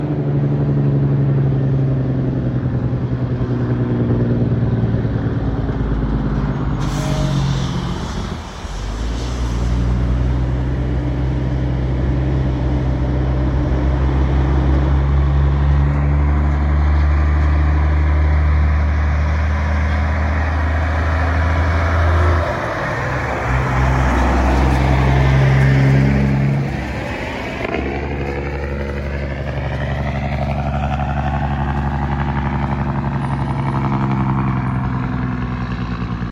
806 getting it done on 11 axels with a mc closkey C4 cone crusher